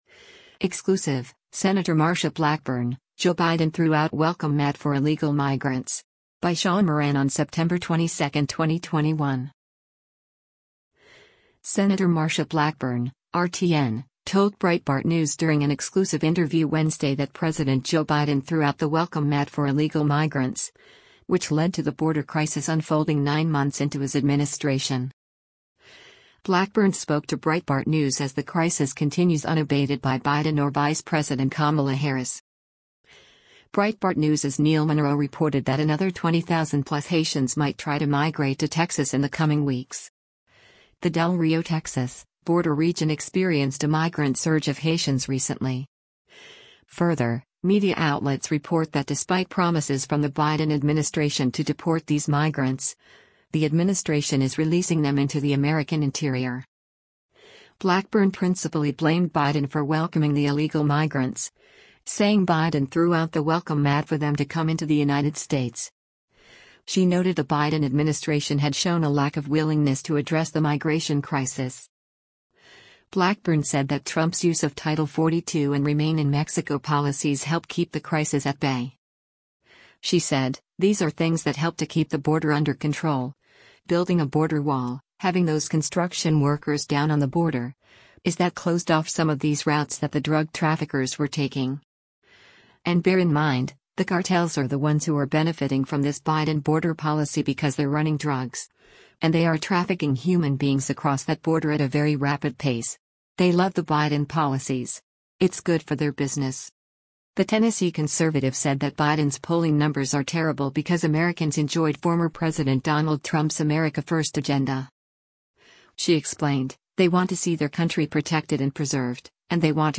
Sen. Marsha Blackburn (R-TN) told Breitbart News during an exclusive interview Wednesday that President Joe Biden threw out the “welcome mat” for illegal migrants, which led to the border crisis unfolding nine months into his administration.